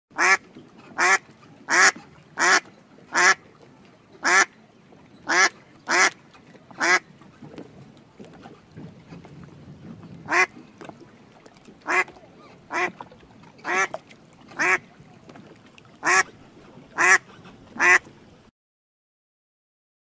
دانلود آهنگ اردک 3 از افکت صوتی انسان و موجودات زنده
دانلود صدای اردک 3 از ساعد نیوز با لینک مستقیم و کیفیت بالا
جلوه های صوتی